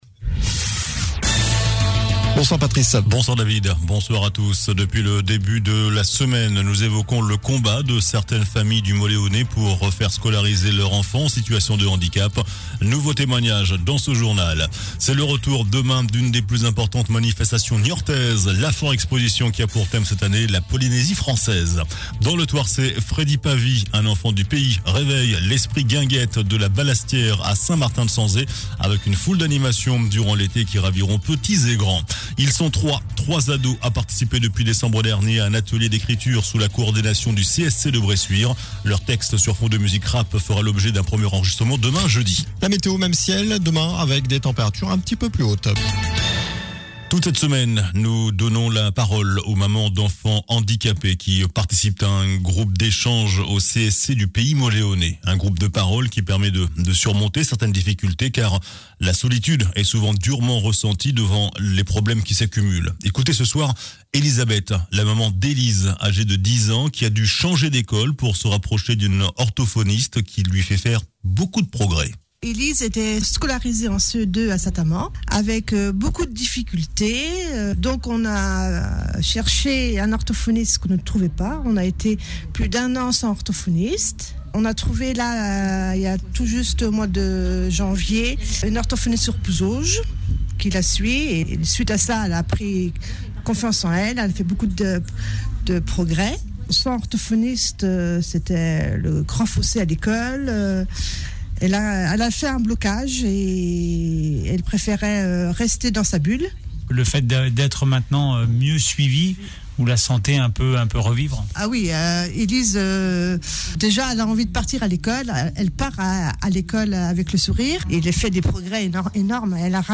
JOURNAL DU MERCREDI 27 AVRIL ( SOIR )